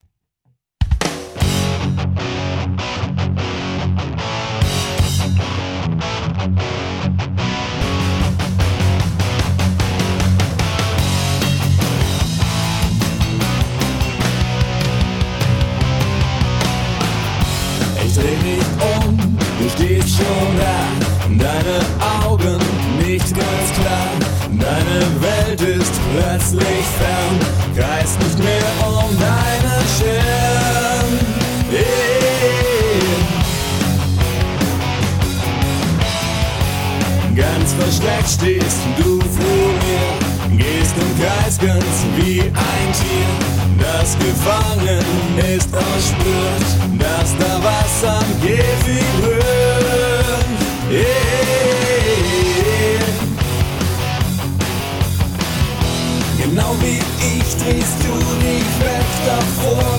Damit bekommt man immerhin die Stimme eben mal so etwas weiter nach vorn.
Hier ist mal ein 60 sekunden schnipsel in "umsonst qualität" mit etwas lauteren vocals dran: Anhänge Spiegelblind_mixed.mp3 Spiegelblind_mixed.mp3 1,8 MB